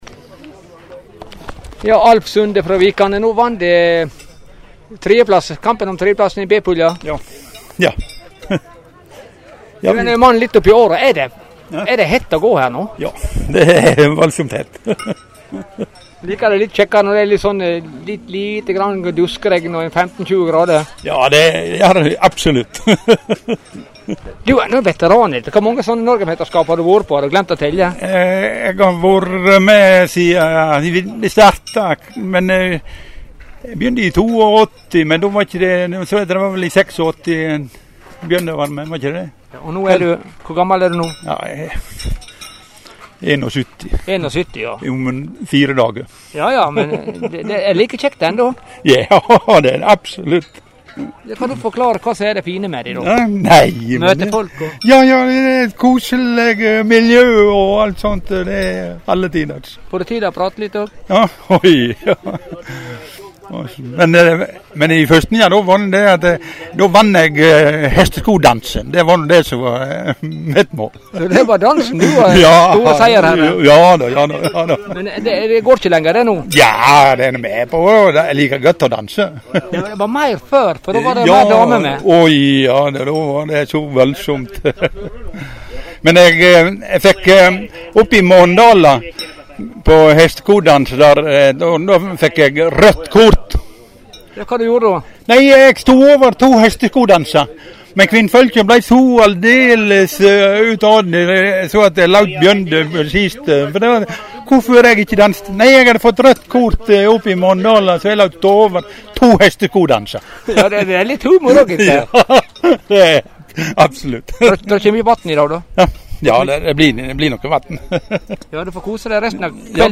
Lydklipp frå NM i Hesteskokasting
Intervju